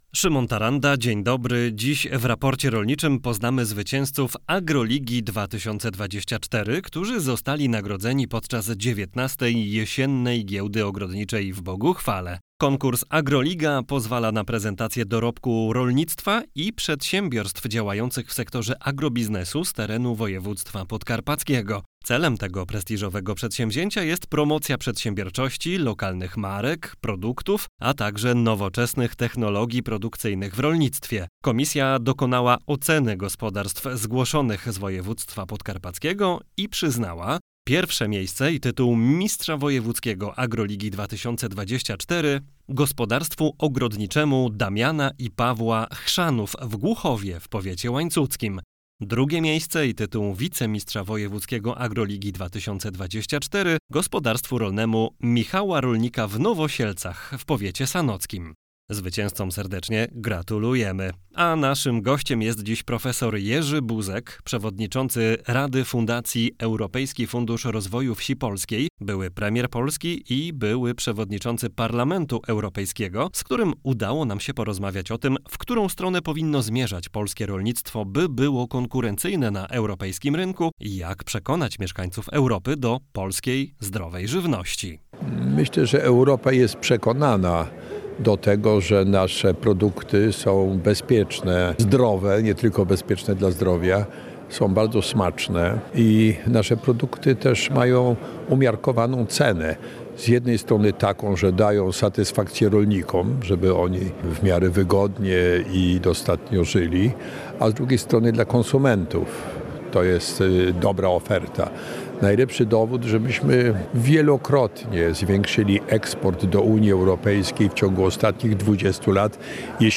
Gościem programu jest prof. Jerzy Buzek, przewodniczący Rady Fundacji Europejski Fundusz Rozwoju Wsi Polskiej, były premier Polski i były przewodniczący Parlamentu Europejskiego, z którym rozmawiamy o tym, w którą stronę powinno zmierzać polskie rolnictwo i jak przekonać mieszkańców Europy do polskiej zdrowej żywności.